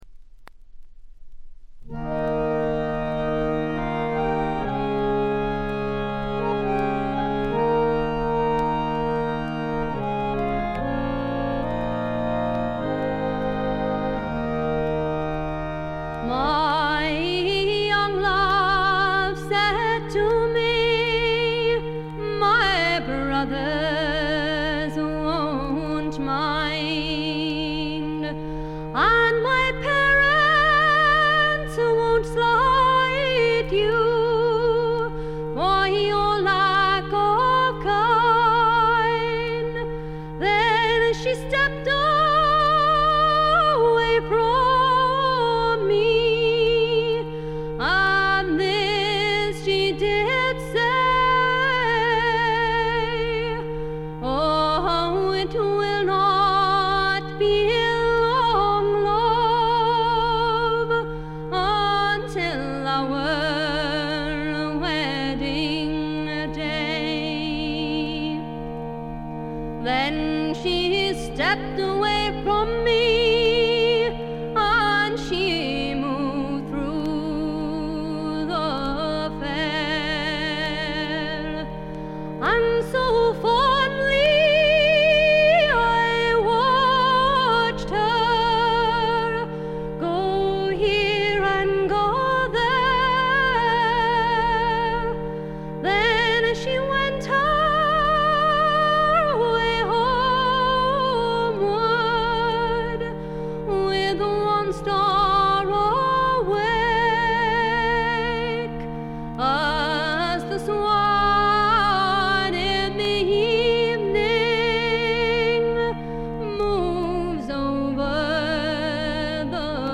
ホーム レコード：英国 フォーク / トラッド
わずかなバックグラウンドノイズ、チリプチ程度。
試聴曲は現品からの取り込み音源です。